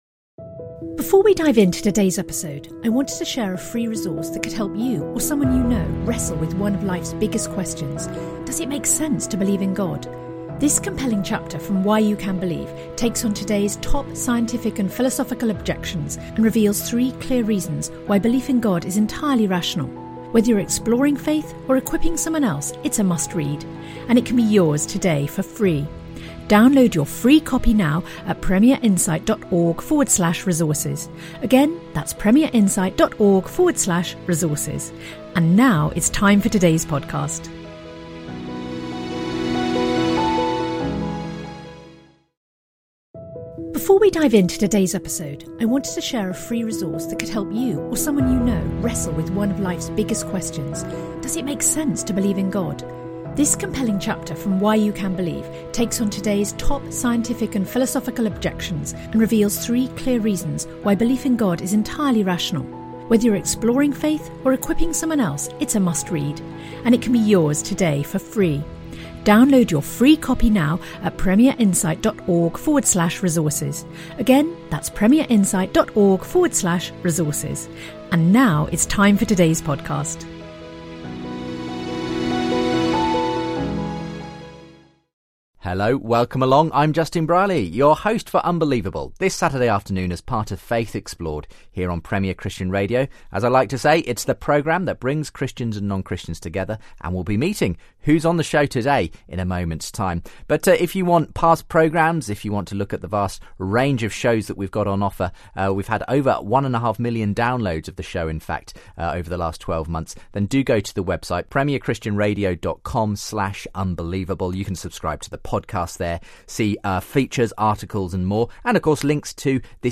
They take calls from atheist listeners on whether prayer makes sense if God already know what we'll ask for, whether penal atonement is just, and why God remains hidden.